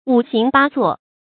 五行八作 注音： ㄨˇ ㄏㄤˊ ㄅㄚ ㄗㄨㄛˋ 讀音讀法： 意思解釋： 泛指各行各業。